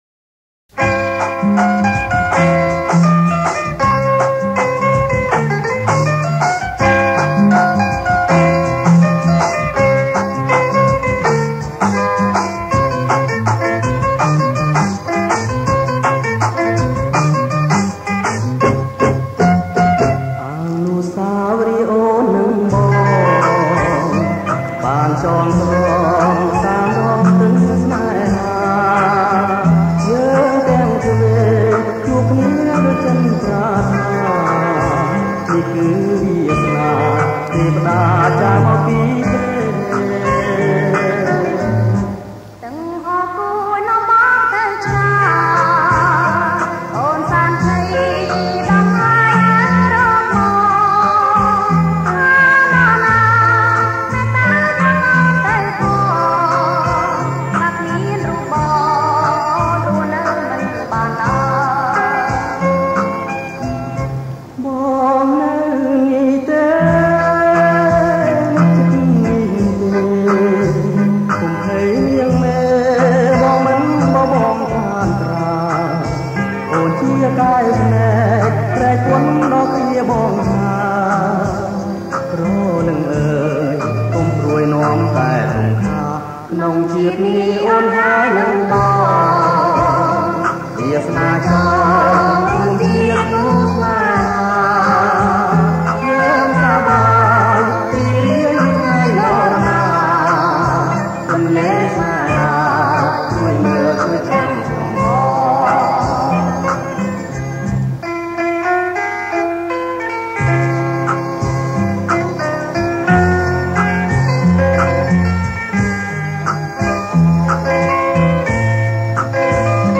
ប្រគំជាចង្វាក់ Bossanova Bolero